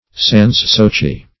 sans-souci - definition of sans-souci - synonyms, pronunciation, spelling from Free Dictionary Search Result for " sans-souci" : The Collaborative International Dictionary of English v.0.48: Sans-souci \Sans`-sou`ci"\, adv.